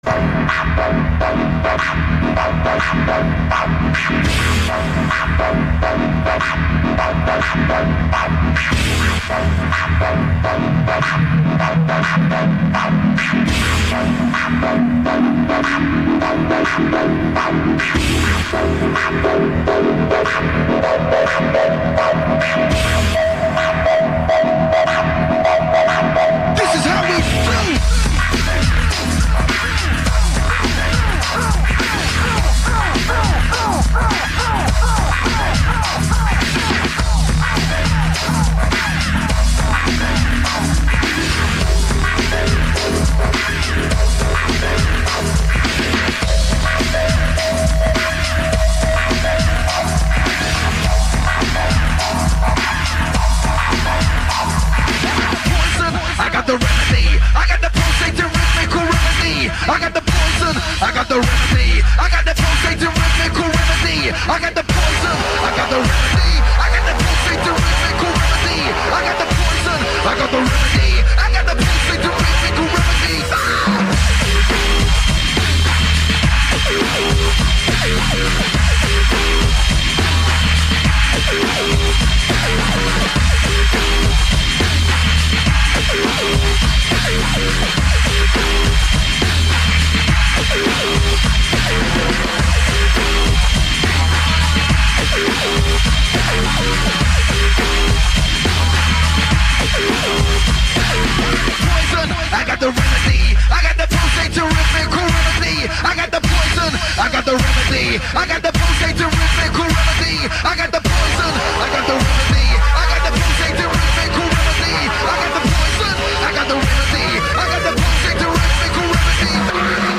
has only been played on radio, that has flute and
beatboxing. the flute comes in at about 2:30 and beatboxing